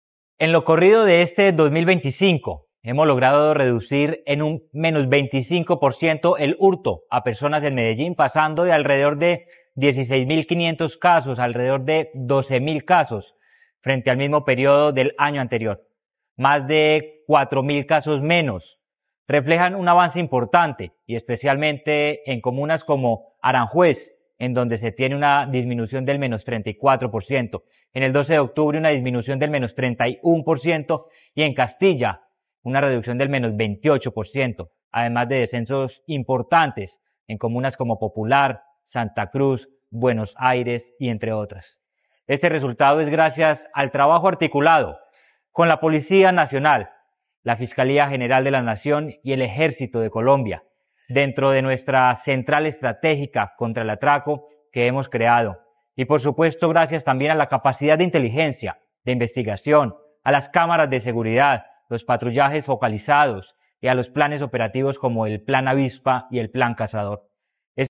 Declaraciones-secretario-de-Seguridad-y-Convivencia-Manuel-Villa-Mejia.mp3